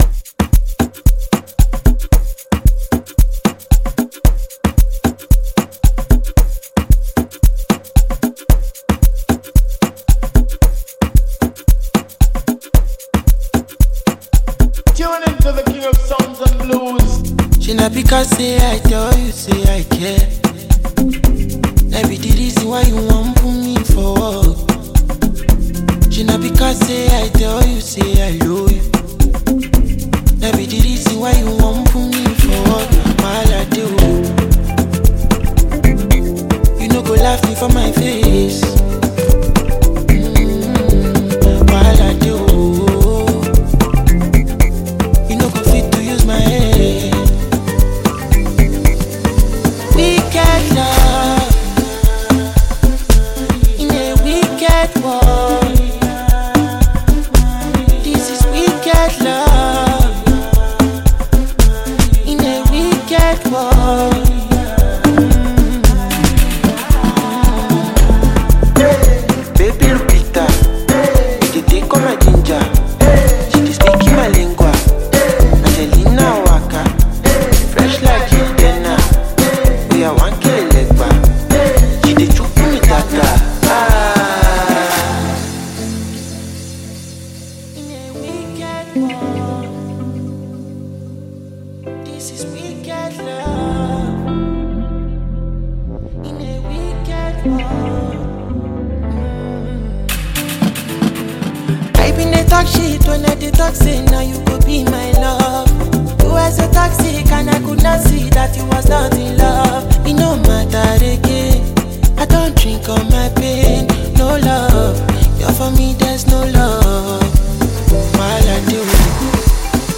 Scorching Talented Nigerian Singer